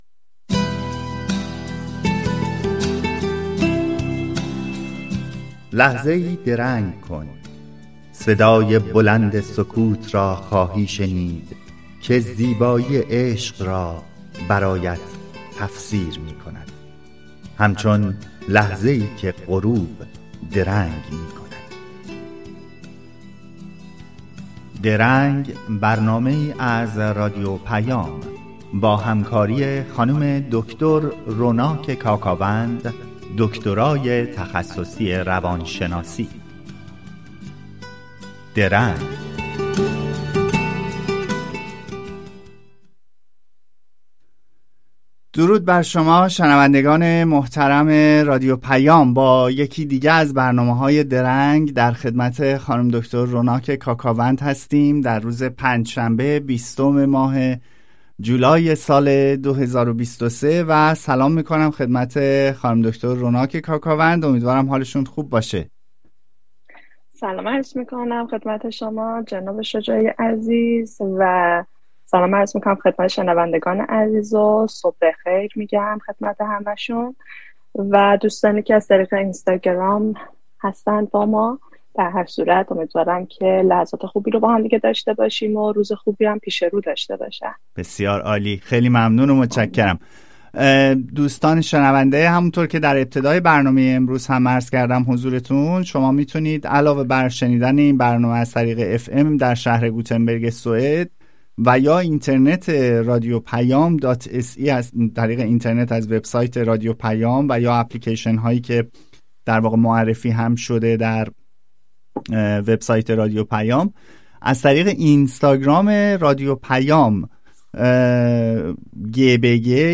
شما در این صفحه می‌توانید به برنامهٔ «درنگ» که هر هفته به‌صورت زنده از رادیو پیام گوتنبرگ سوئد پخش می‌شود، گوش دهید. این برنامه با هدف پرداختن به موضوعات متنوع اجتماعی، روانشناختی و فرهنگی تهیه و ارائه می‌شود.